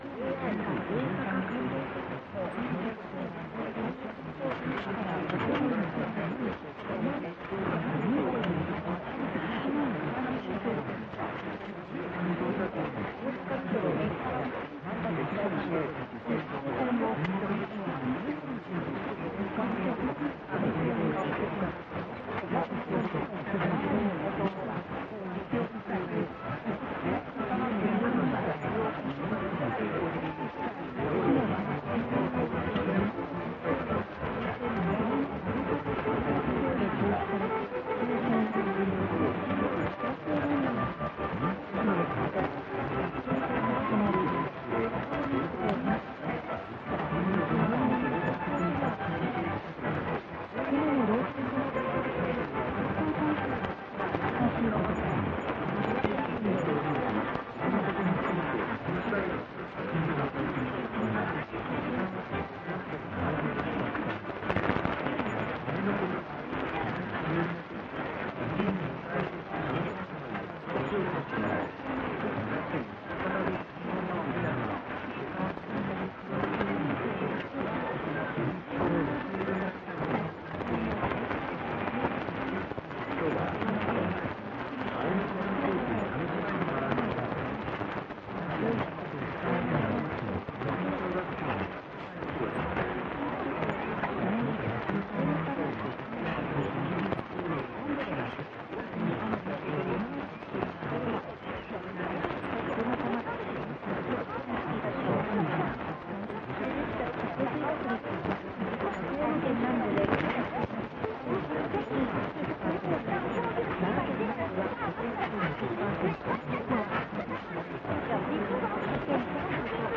台湾と思われる局が聞こえていました。
偶然にも、4日の受信音と同じ曲が流れている…ようにも聞こえます。
受信音、ひどい状態ですが、UPしておきます。
<受信地:岩手県 RX:SIHUADON D-808>